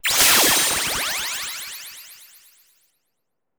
Glyph Activation Light 01.wav